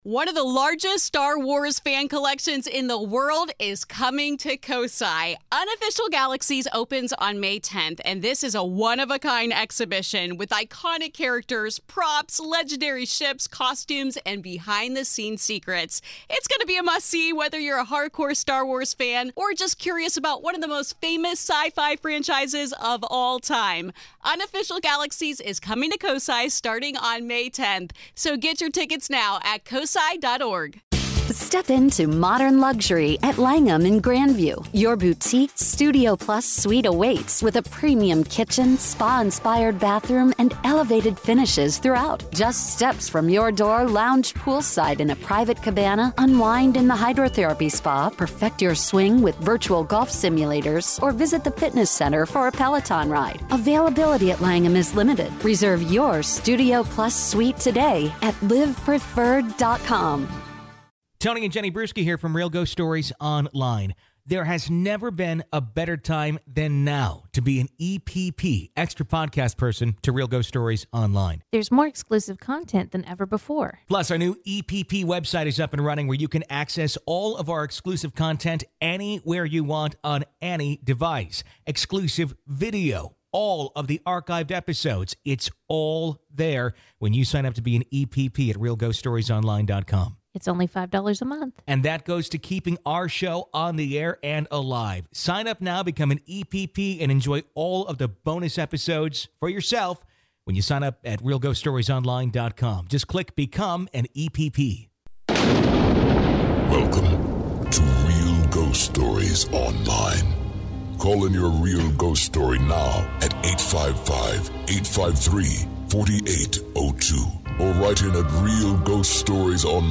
take your calls during an "open lines" episode of the web's best ghost show Real Ghost Stories Online.